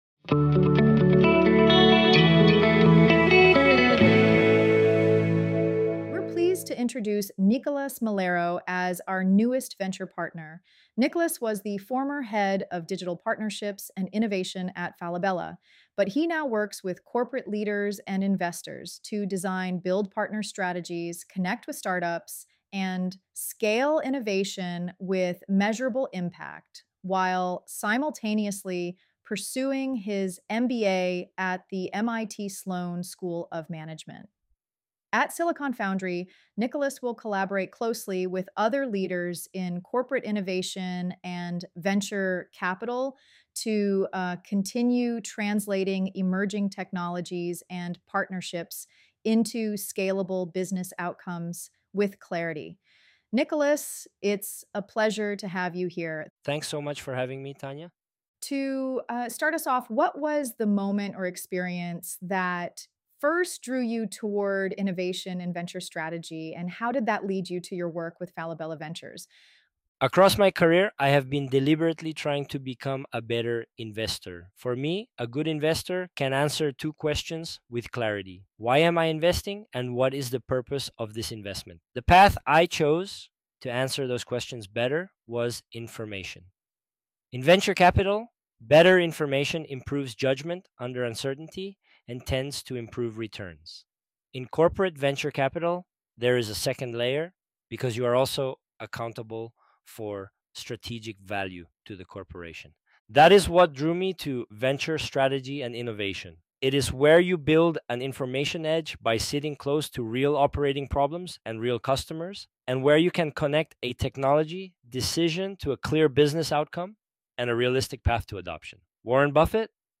Press play to listen to this conversation https